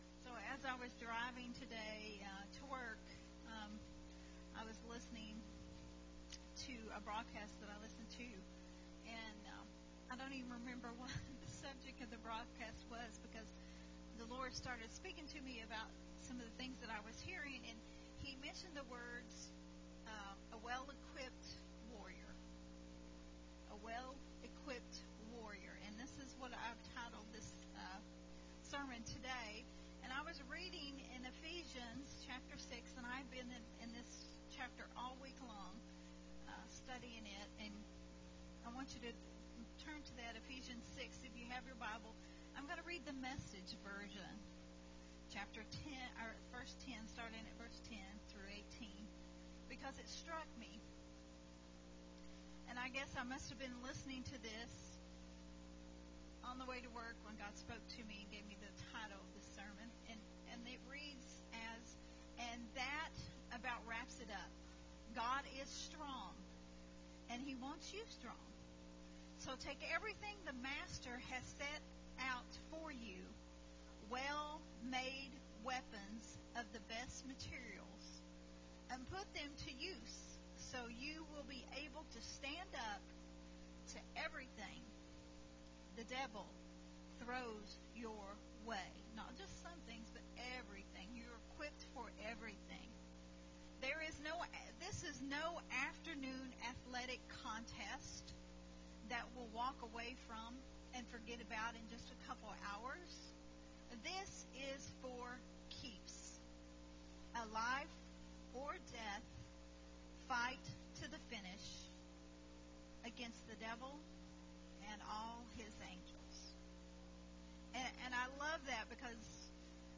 a Family Training Hour teaching
recorded at Unity Worship Center on June 15th, 2022